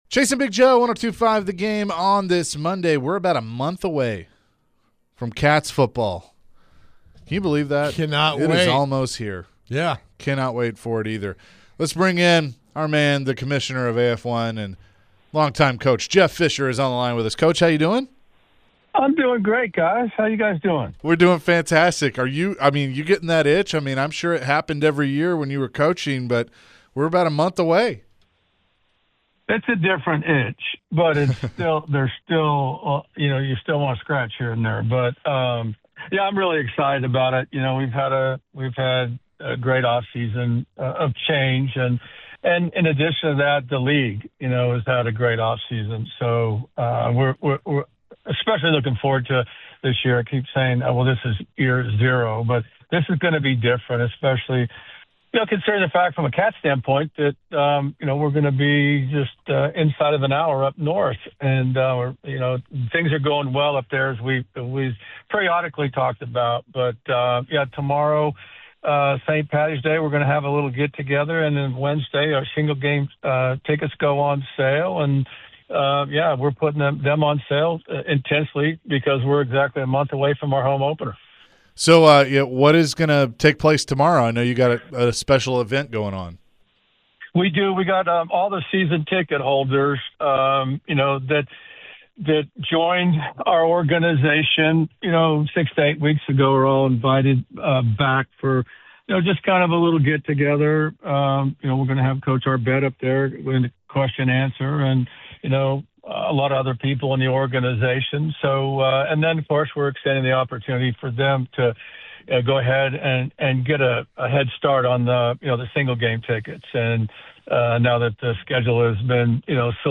AF1 Commissioner Jeff Fisher joined the show to preview the upcoming AF1 season. Jeff mentioned his thoughts on the Titans' new jerseys and logos.